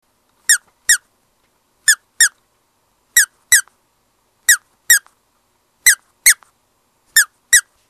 ９条に引っかけて、「きゅ〜」 と鳴るグッズを探していたのですが、ペット用のおもちゃでカワイイのがあったのでゲット。
おまけ＝きゅ〜ちゃんのなきごえ
（★MP3けいしきのおんせいファイルです。「きゅうきゅう」ってきこえるかな？）